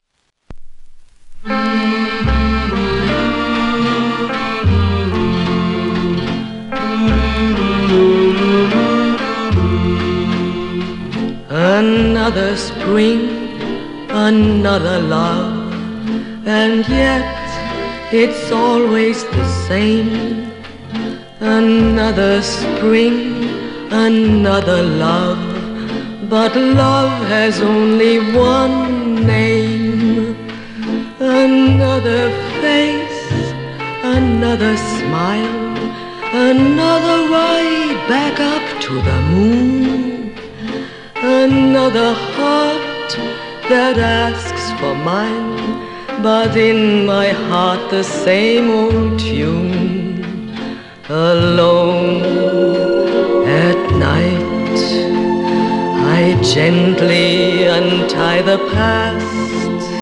w/オーケストラ
1957年頃の録音